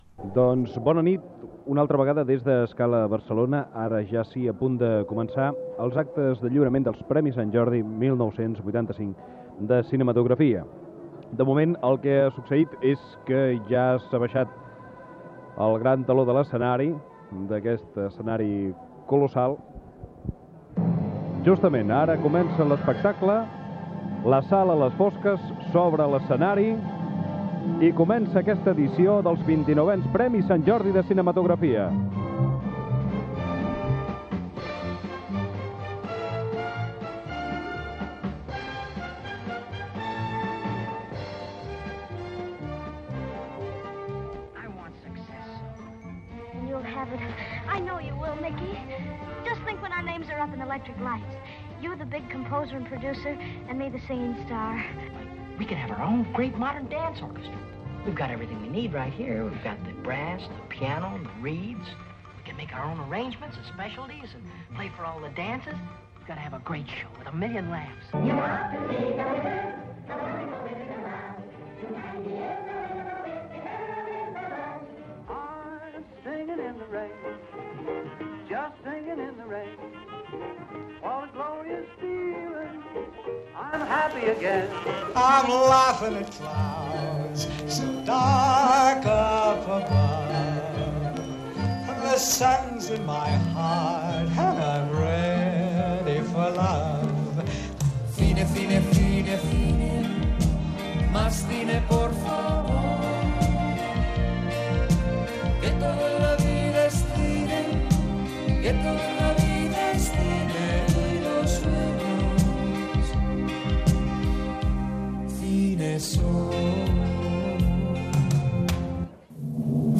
2d74a431c340ac8277e7c3b36e5fe16d6c8b756a.mp3 Títol Ràdio 4 Emissora Ràdio 4 Cadena RNE Titularitat Pública estatal Nom programa Premis Sant Jordi de Cinematografia de RNE Descripció Transmissió dels 29 Premis Sant Jordi de Cinematografia des de la sala Scala deBarcelona. Presentació, fragments de pel·lícules, sintonia orquestral de RNE